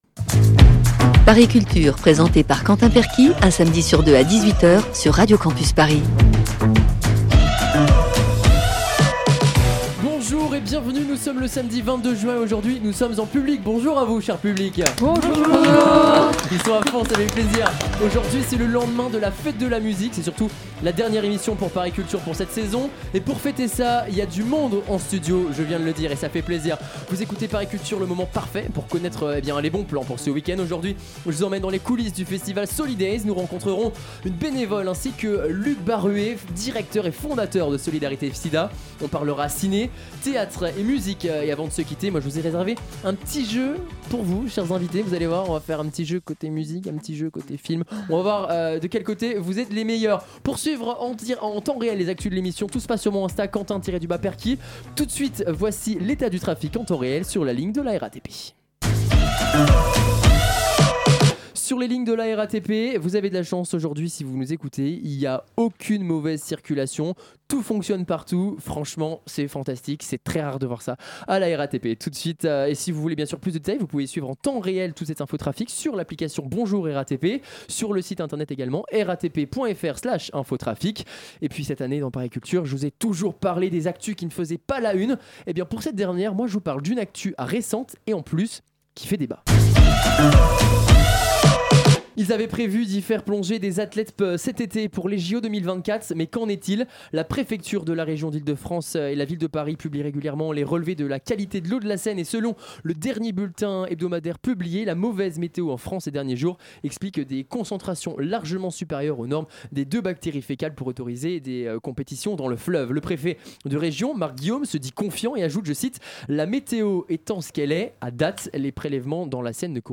Pour cette dernière émission... vous avez fait le déplacement !
Magazine Culture